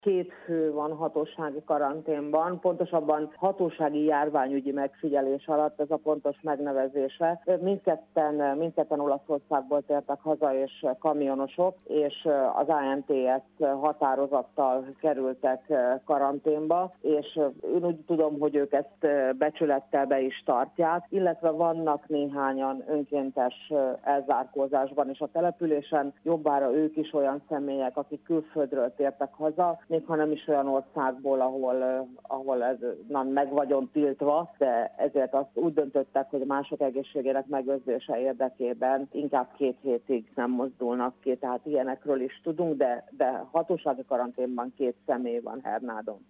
Ketten vannak hatósági járványügyi megfigyelés alatt Hernádon. A két fő kamionsofőrként dolgozik, Olaszországból tértek vissza, így automatikusan el kellett zárni magukat. Zsírosné dr. Pallaga Mária polgármester elmondta, úgy értesült, becsületesen be is tartják a karantén szabályait.